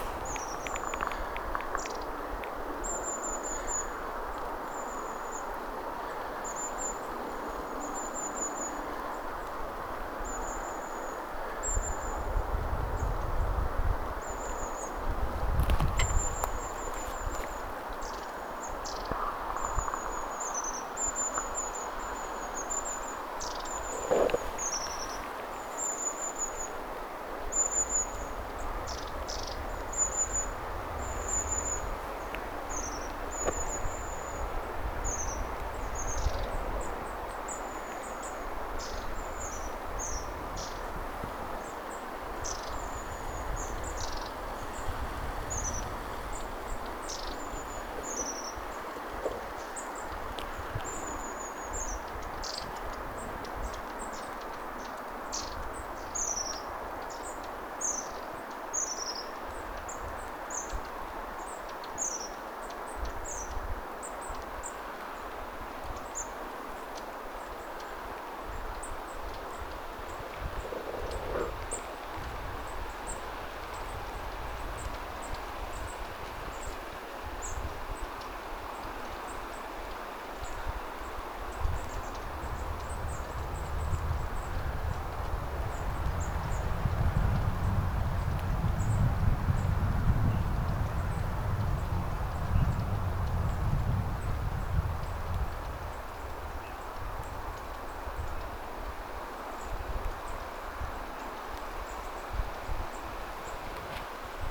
pyrstötiaisia tuli ihan pään päälle
pyrstotiaisia_ihan_paalla_puiden_latvuksissa.mp3